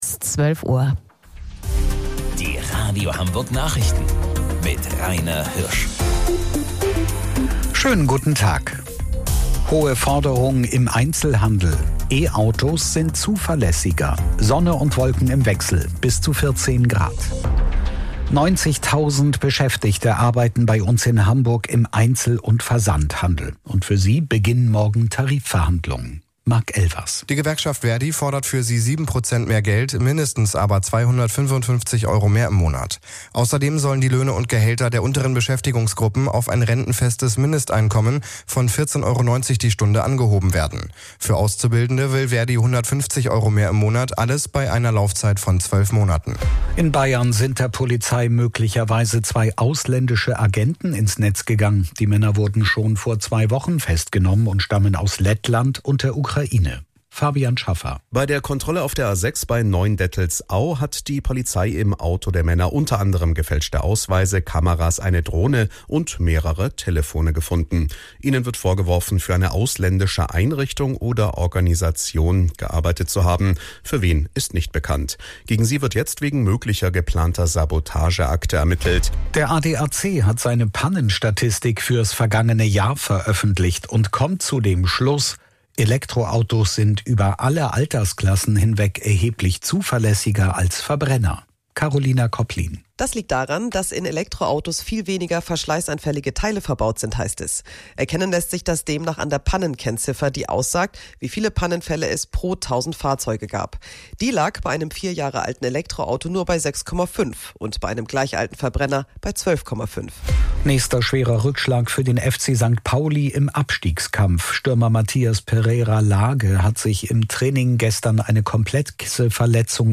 Radio Hamburg Nachrichten vom 23.04.2026 um 12 Uhr